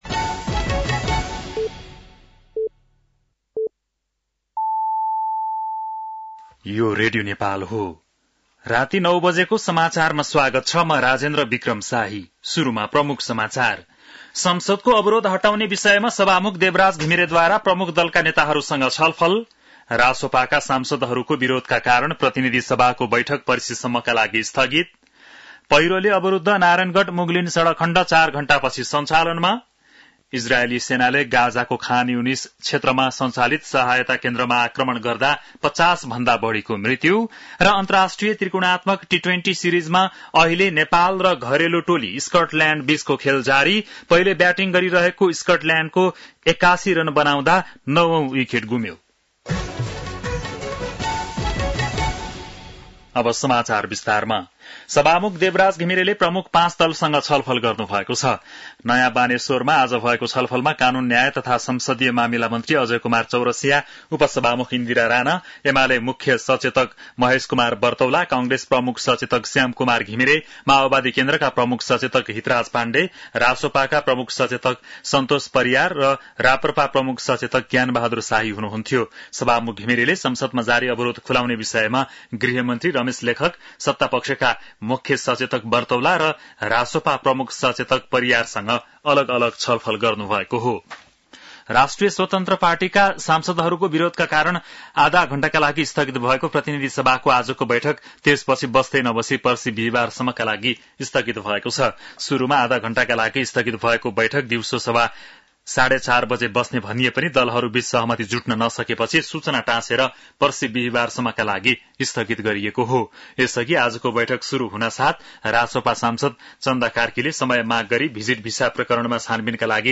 बेलुकी ९ बजेको नेपाली समाचार : ३ असार , २०८२